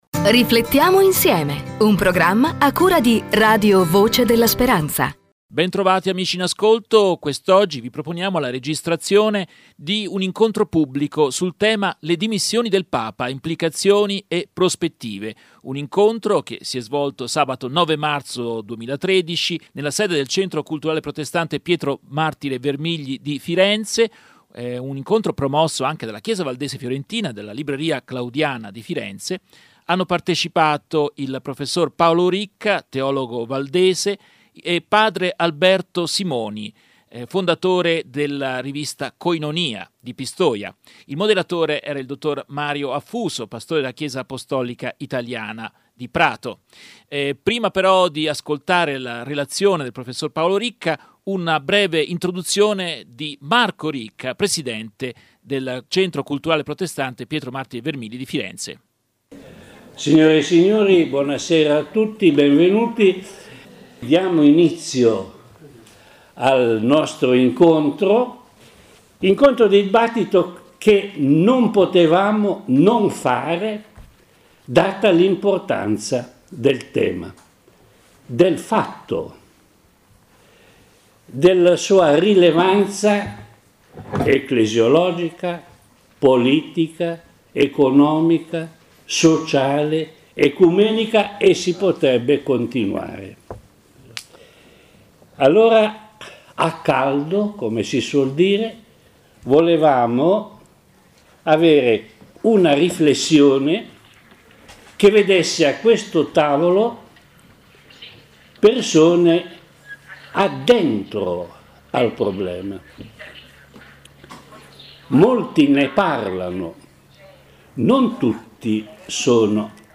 Registrazione dell’incontro-dibattito che si e’ svolto nel centro sociale valdese di Firenze il 9 marzo 2013, organizzato dal Centro culturale protestante Pietro Martire Vermigli di Firenze e dalla libreria Claudiana